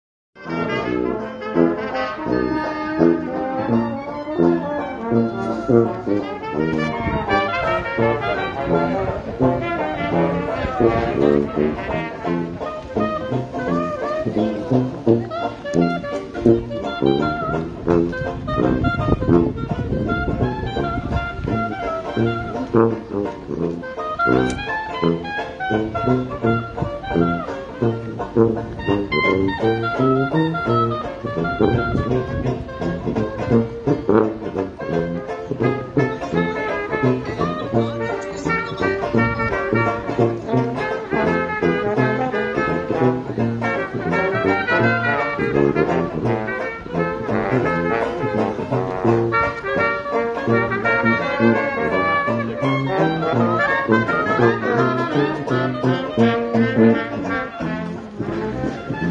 BUSKING AT THE MEADOWS